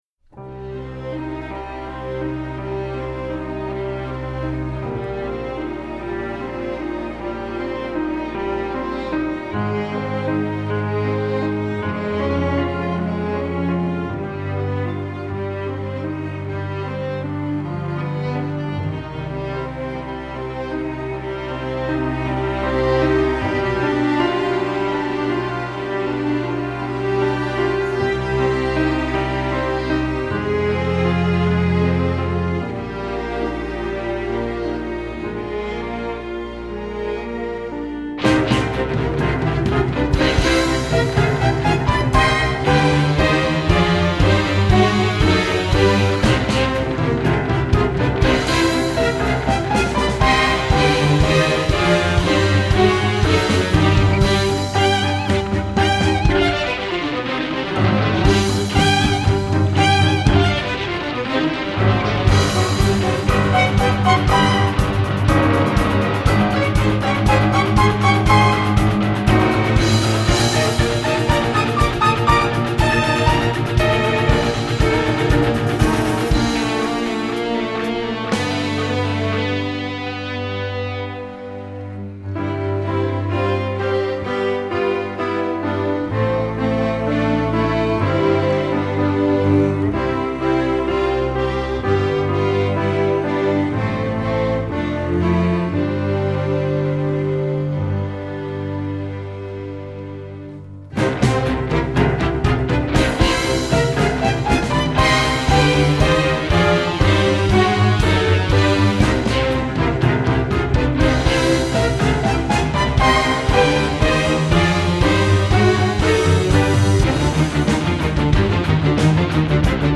pop, rock, secular